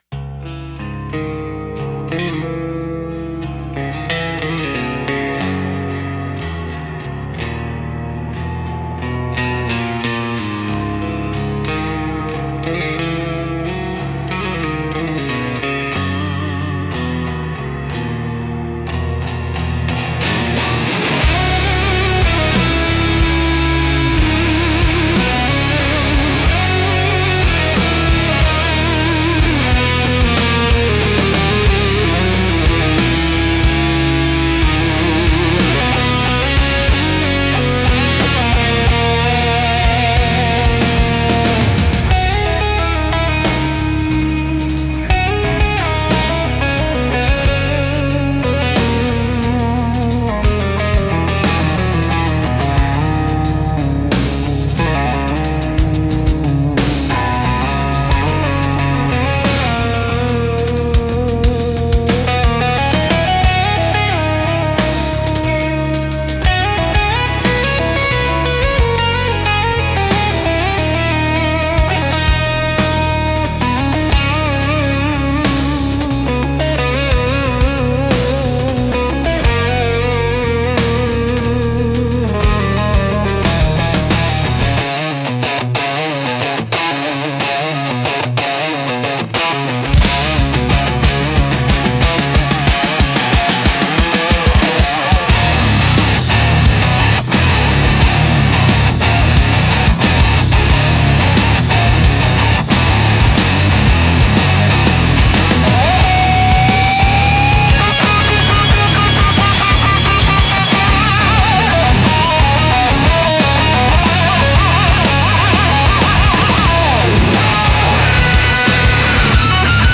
Instrumental - Husk of a Hymn 4.04